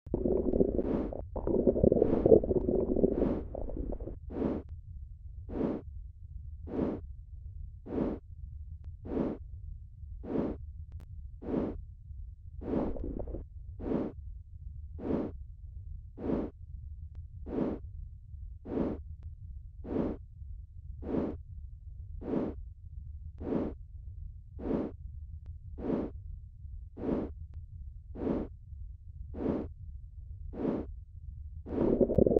Auscultación
soplos pueden sugerir aneurisma.
Normal_bowel_sounds_with_bruits_in_upper_quadrants.mp3